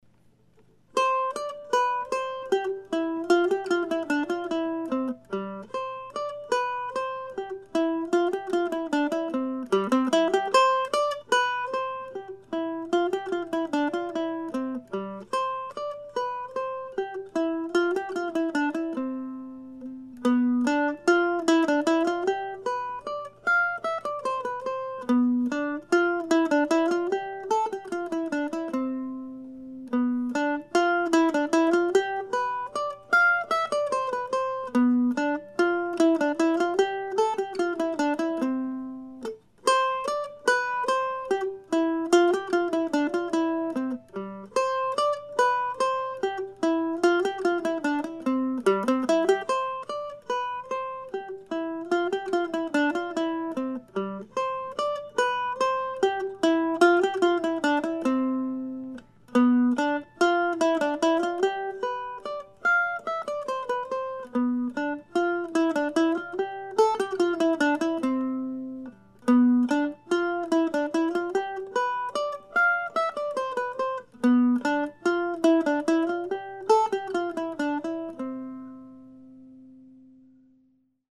Here it is presented as a solo piece in the key of C.